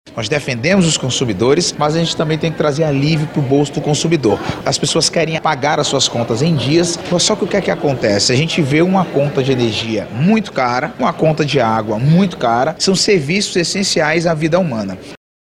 O presidente da Comissão de Defesa do Consumidor da Aleam, deputado Mário César Filho, destaca que a medida contribui para a economia do estado.